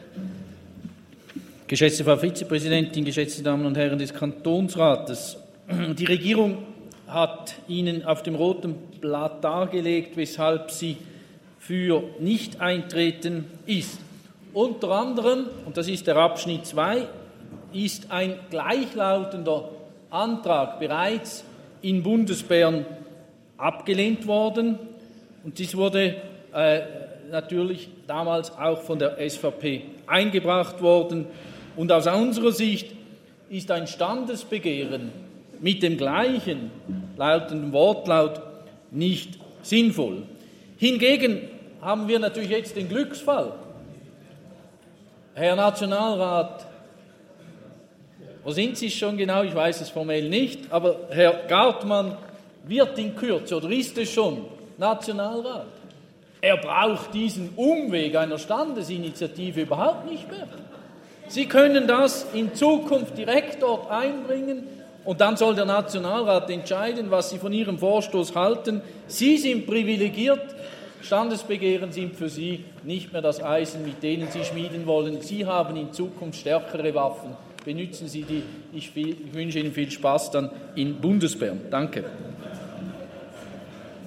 Session des Kantonsrates vom 27. bis 29. November 2023, Wintersession
28.11.2023Wortmeldung